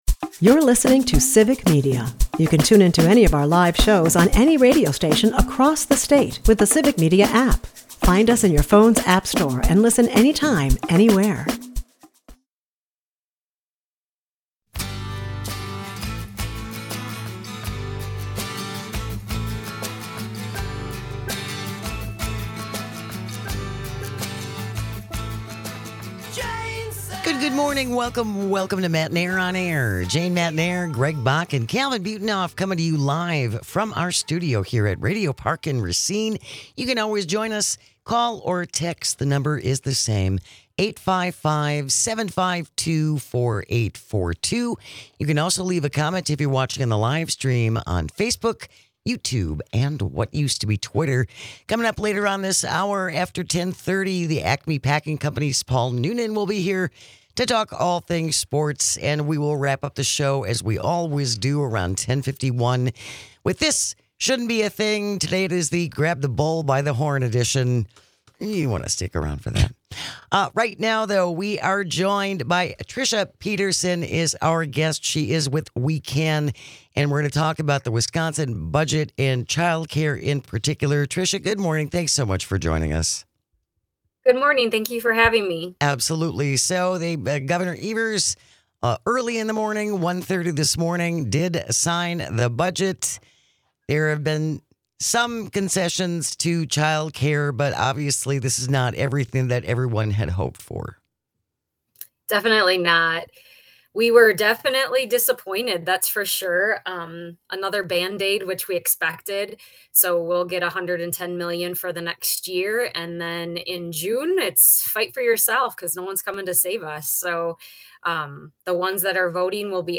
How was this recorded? Let's celebrate the start of another great week with This Shouldn't Be A Thing - Hush Your Mouth Edition Matenaer On Air is a part of the Civic Media radio network and airs Monday through Friday from 9 -11 am across the state.